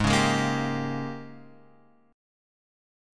G#M7sus2sus4 chord {4 4 6 3 x 3} chord